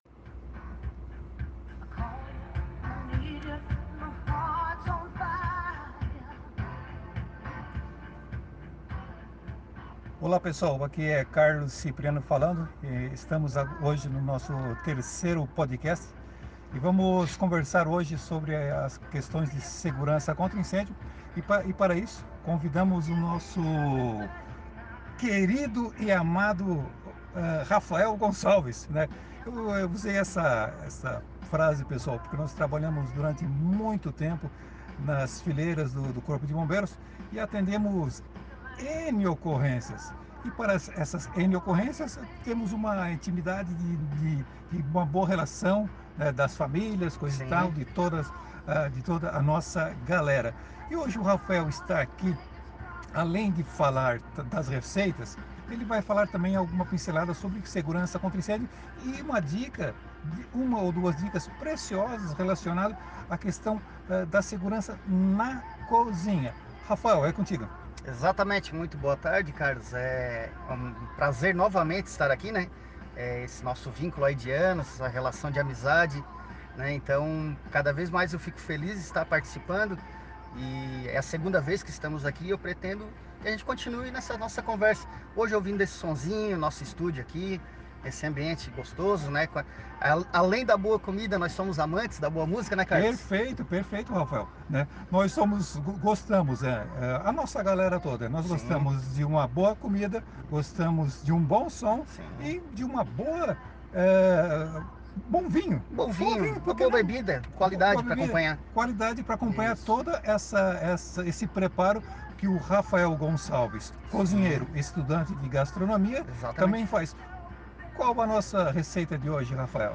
Dois Bombeiros com histórias e estórias da caserna,